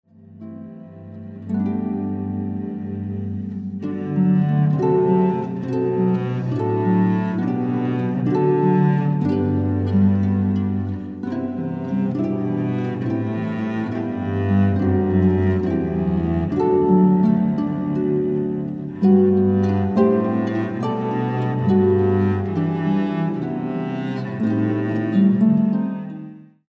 featuring guest accompanists